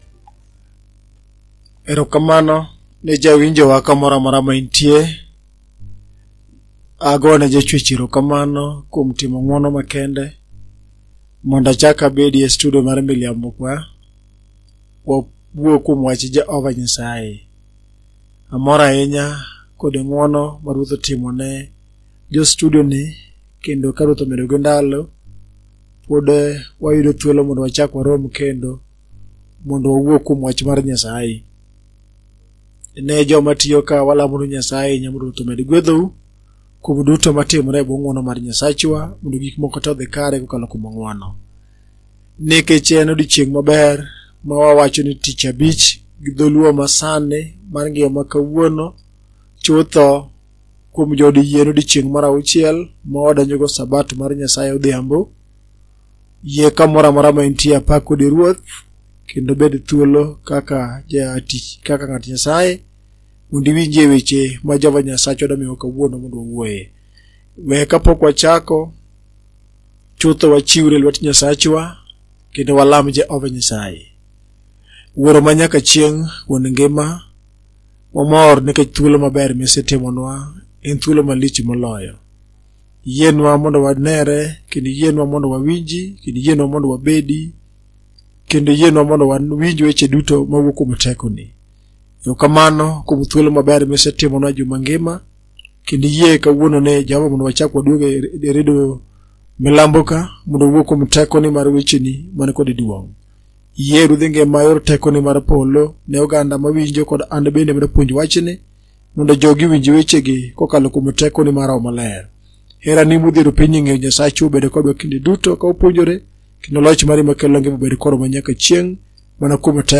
Dholuo Sermons